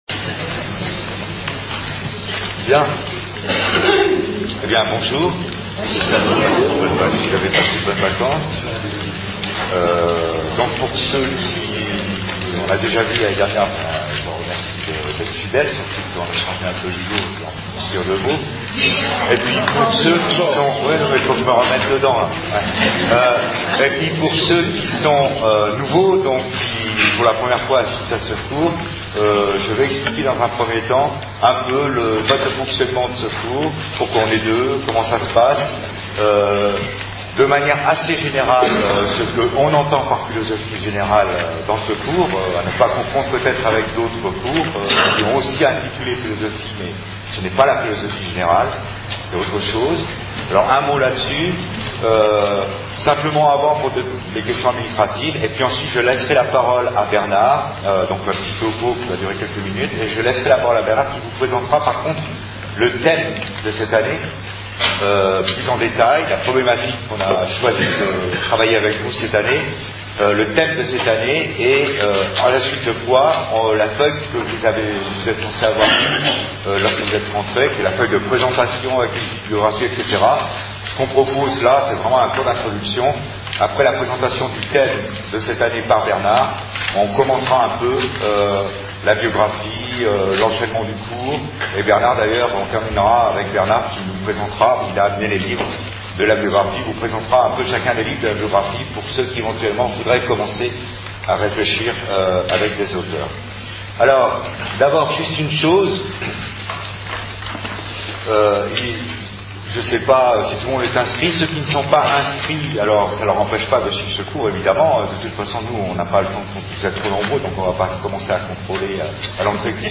Cours de Philosophie UIAD